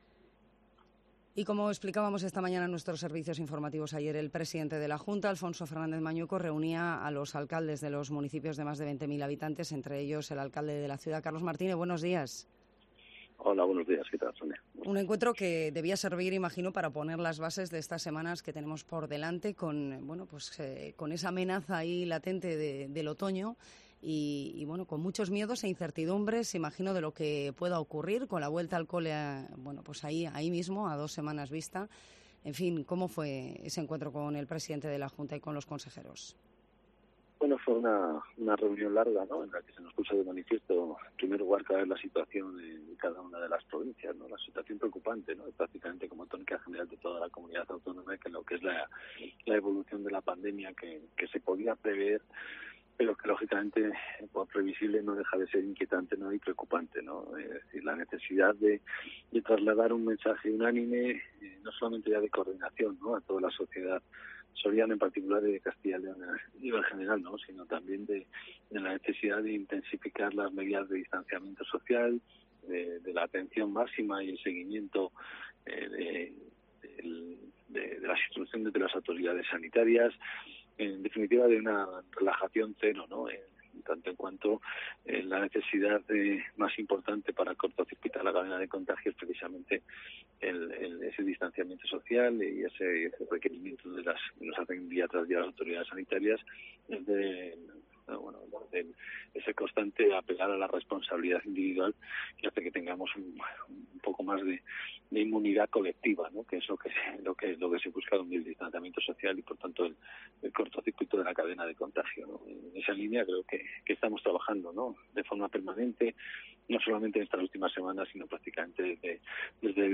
Carlos Martínez habla en Cope Soria de la reunión ayer de Mañueco con los alcaldes de Castilla y León